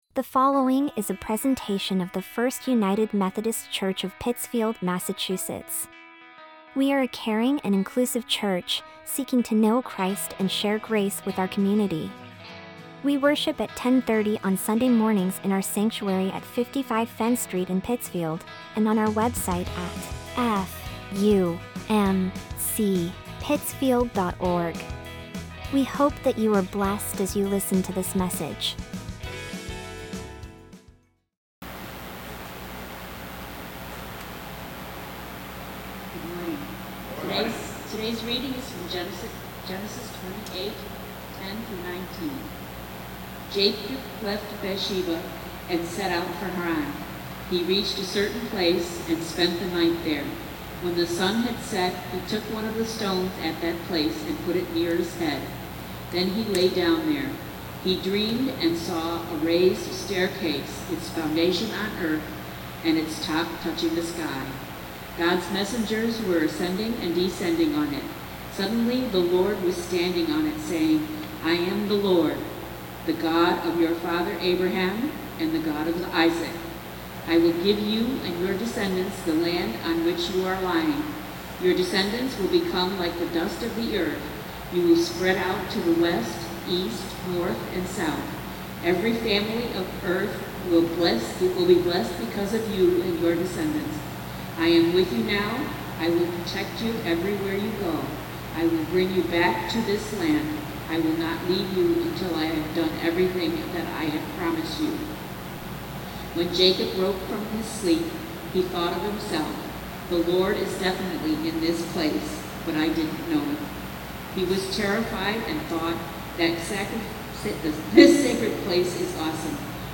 2023 I’ll be there for you Preacher